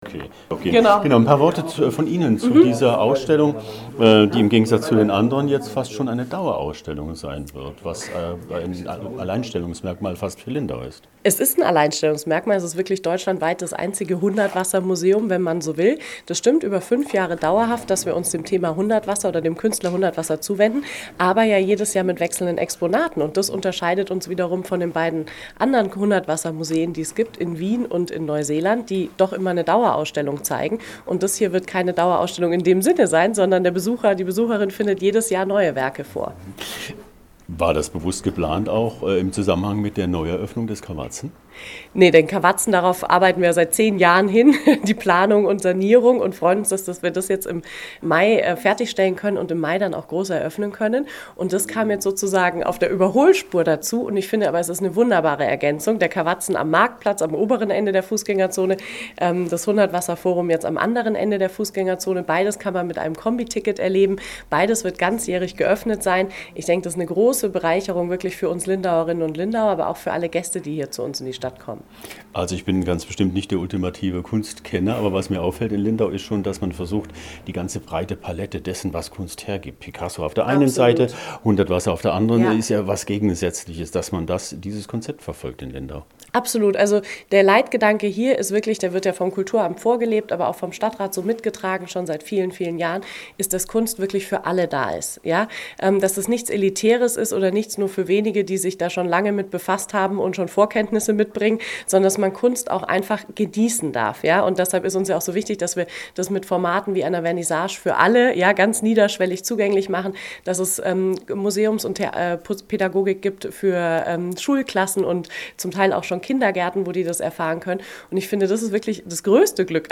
VOLLSTAeNDIGES-Interview-fuer-HOMEPAGE_OB-Alfons_Eroeffnung-Hundertwasser-Ausstellung-Lindau.mp3